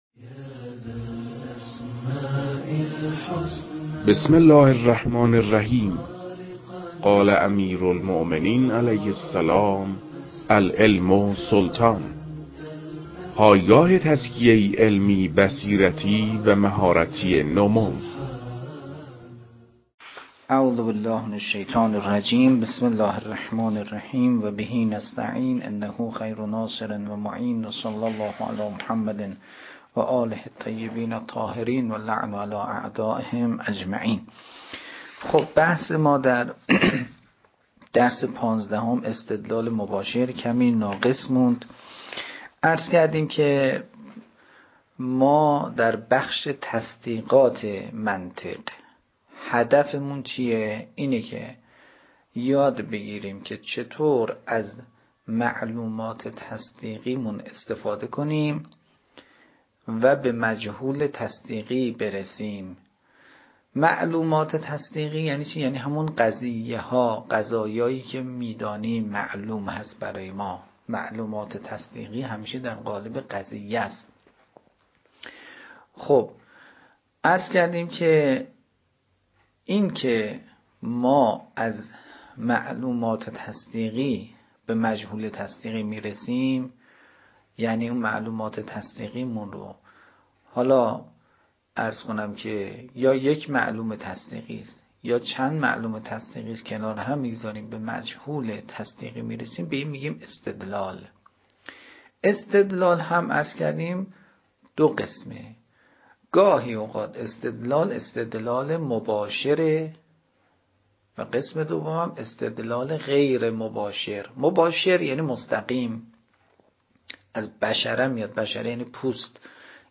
در این بخش، کتاب «درآمدی بر منطق» که اولین کتاب در مرحلۀ آشنایی با علم منطق است، به صورت ترتیب مباحث کتاب، تدریس می‌شود.
در تدریس این کتاب- با توجه به سطح آشنایی کتاب- سعی شده است، مطالب به صورت روان و در حد آشنایی ارائه شود.